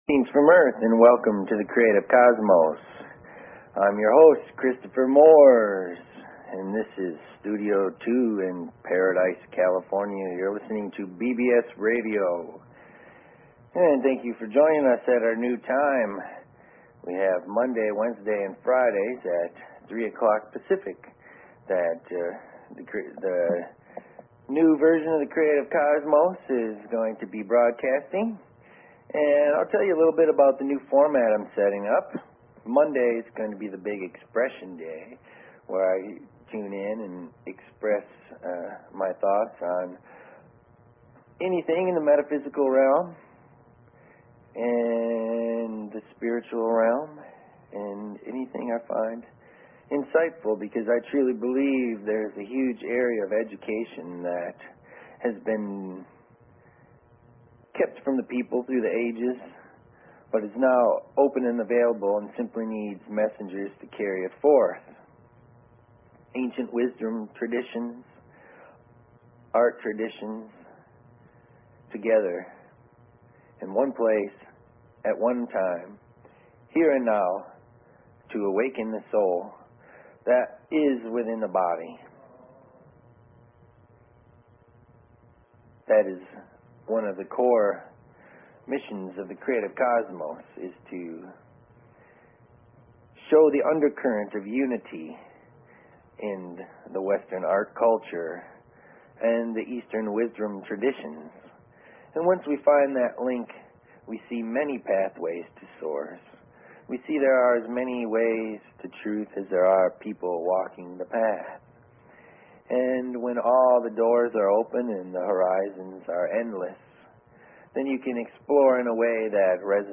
Talk Show Episode, Audio Podcast
Zen, Chakras, Dreams, Astral Plane, Aliens, Spirit Communication, Past Lives, and Soul Awakening are all in play. There are no boundaries as the conversation goes where it needs to in the moment.